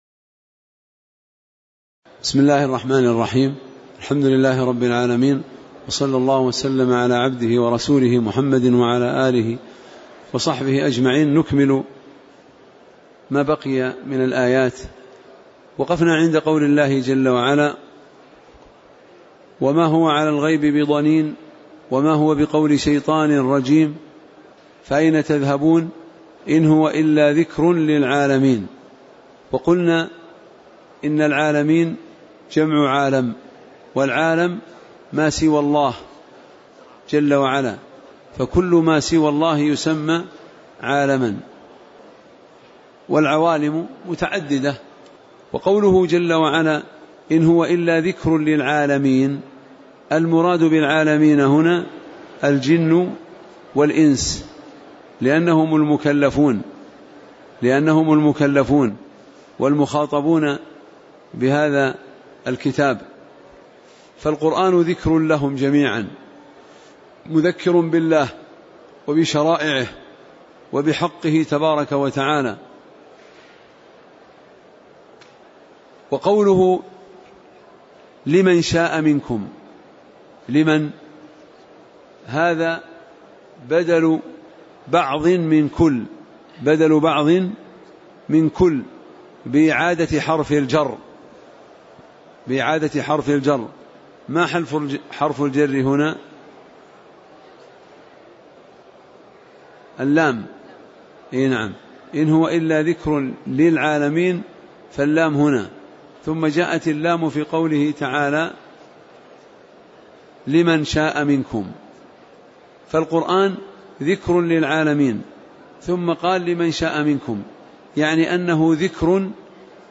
تاريخ النشر ١٥ شوال ١٤٣٨ هـ المكان: المسجد النبوي الشيخ